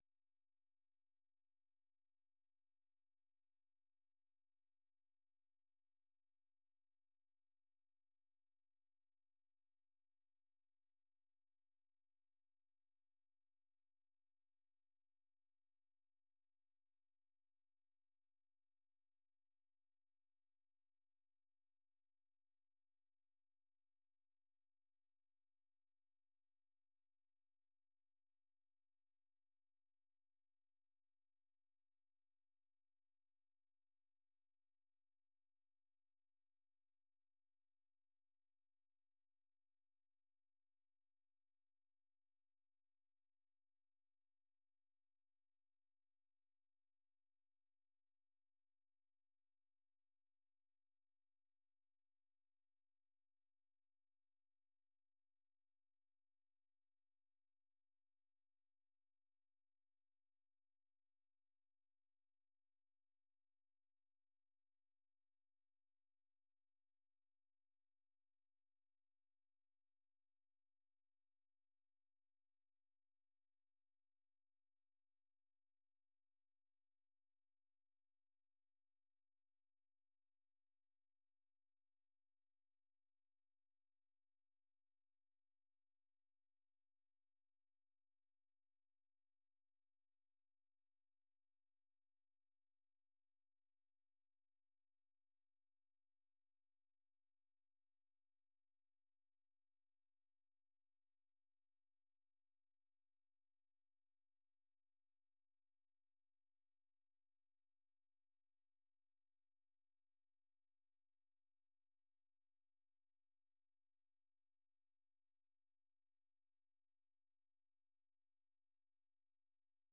VOA 한국어 간판 뉴스 프로그램 '뉴스 투데이' 1부 방송입니다.